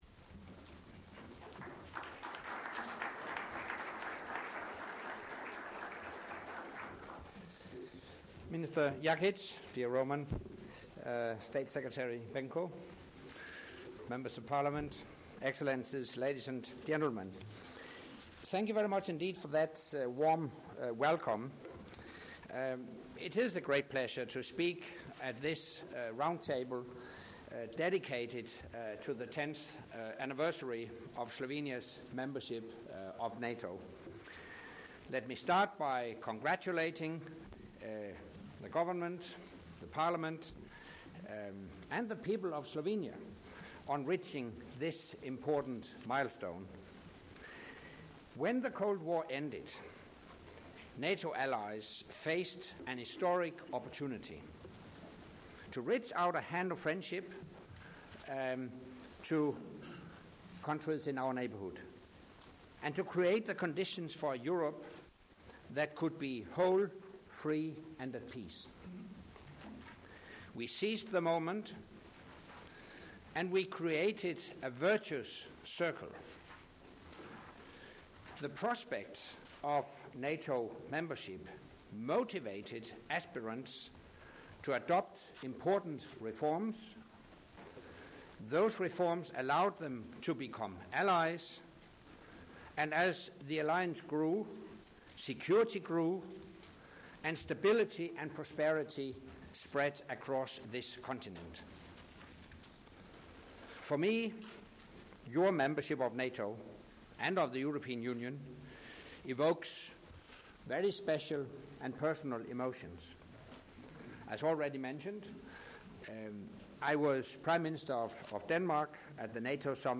Address by NATO Secretary General Anders Fogh Rasmussen at the roundtable on Slovenia's 10 years in NATO at the Chamber of Commerce, Ljubljana, Slovenia 24 Jan. 2014 | download mp3 Joint press point with NATO Secretary General Anders Fogh Rasmussen and the Slovenian Prime Minister Alenka Bratušek 24 Jan. 2014 | download mp3